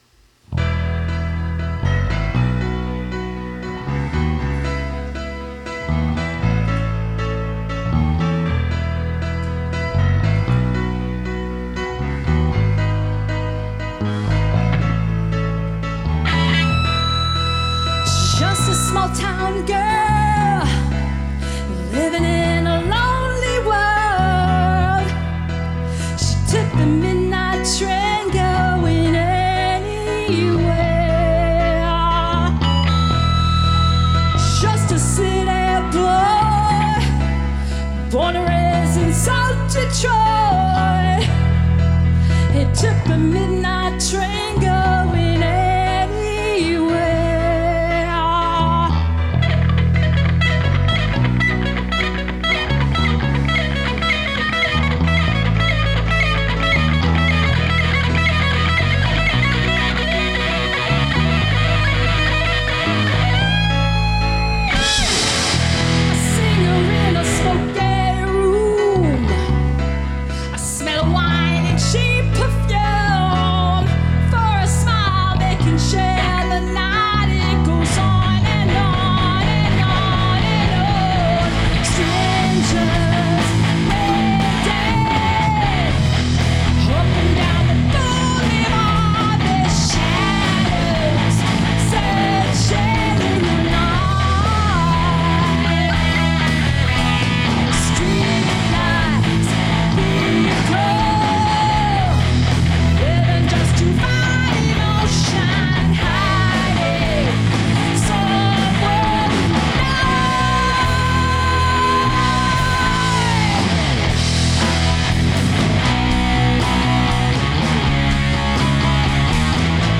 party rock band